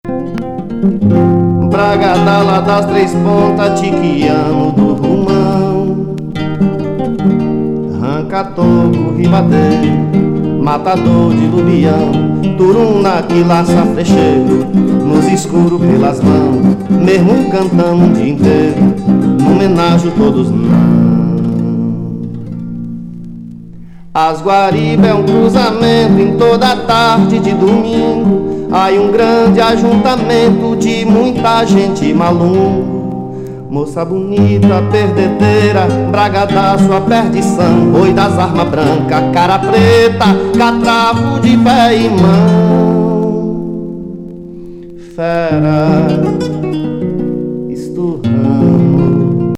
フォークロアなムードが美しい傑作!